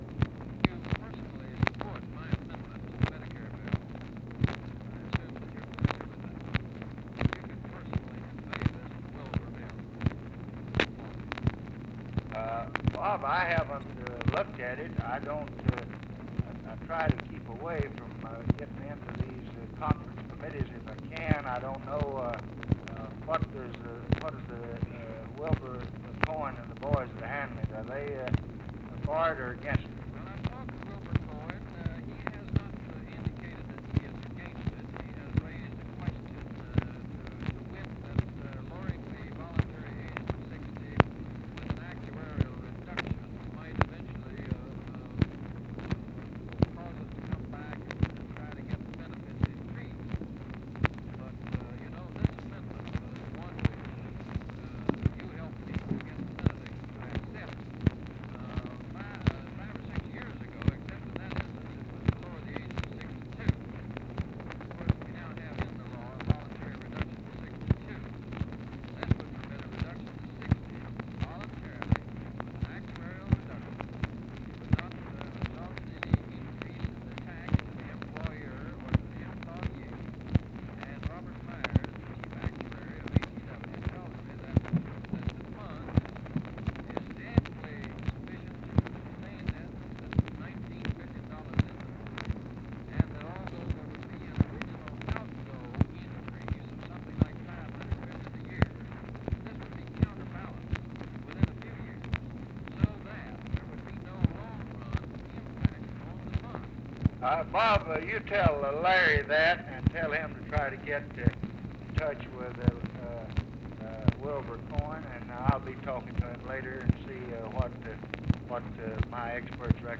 Conversation with ROBERT BYRD, July 15, 1965
Secret White House Tapes